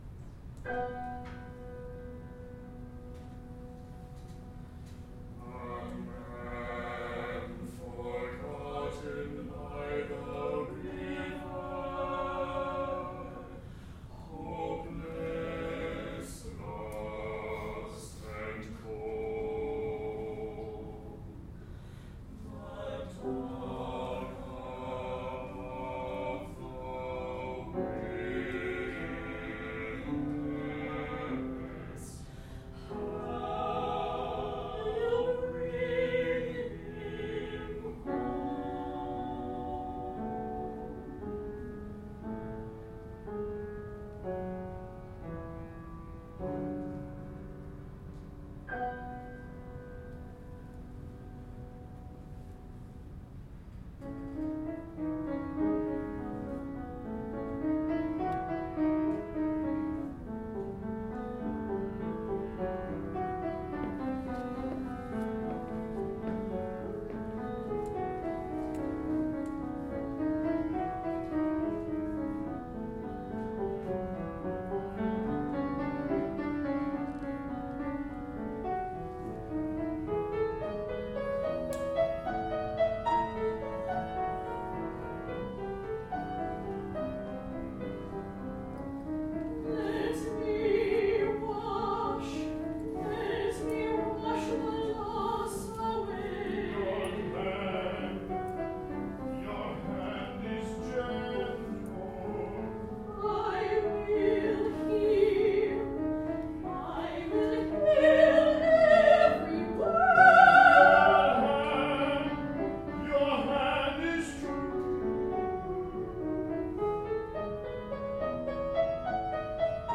Katherine is currently working on a chamber opera libretto, Madonna of the Wilderness,  which contains  “Wash the Loss Away,” with support from the Alberta Foundation for the Arts.
Chorus
piano